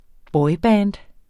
Udtale [ ˈbʌjˌbæːnd ]